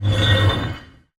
metal_scrape_deep_grind_squeak_03.wav